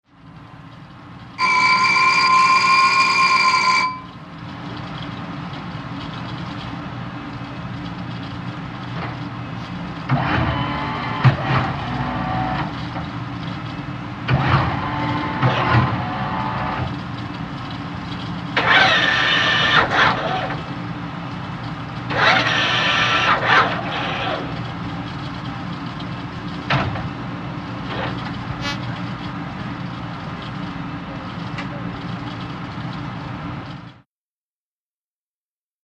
Gun Turret|Exterior | Sneak On The Lot
WEAPONS - VARIOUS GUN TURRET: EXT: Inside gun turret, bell, motor & servo rotation, turn up & down.